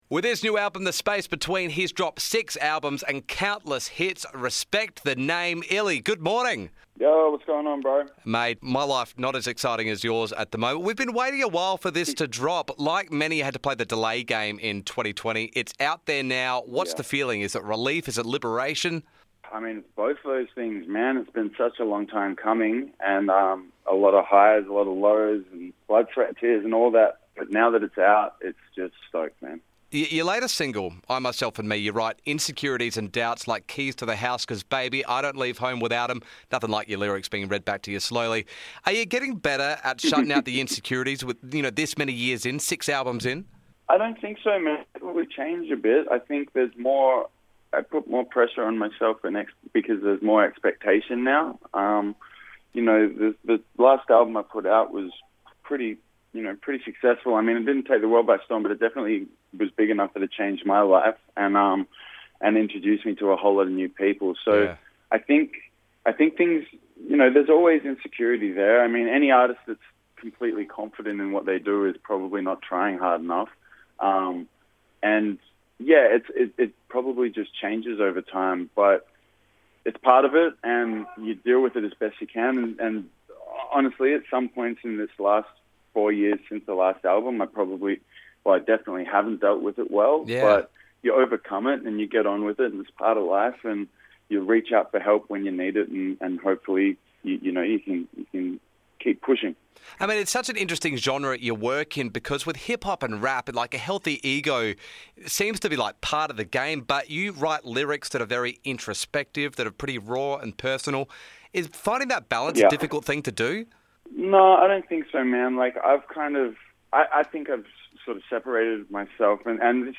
The Space Between is Illy's 6th LP and some of his best work to date. The Aussie hip-hop star talks about the long wait to get the album out, balancing vulnerability and ego and when we might see him next!